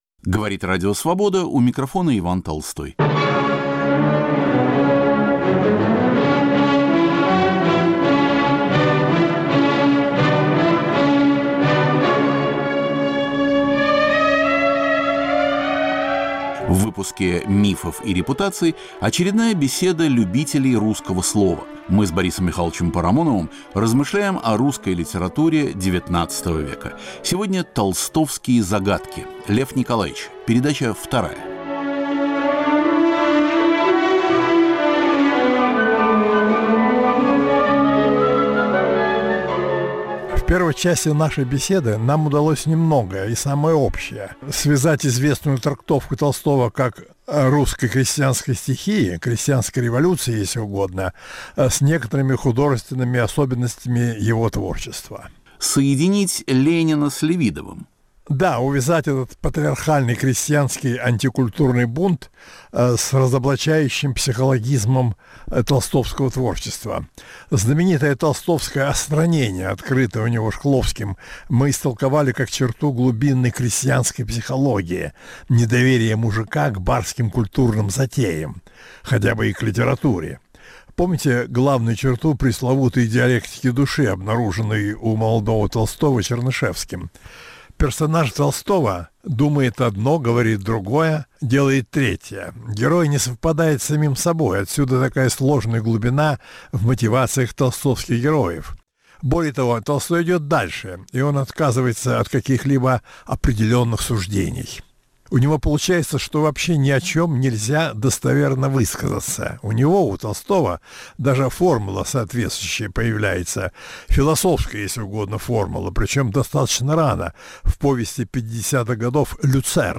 Новая беседа любителей русского слова.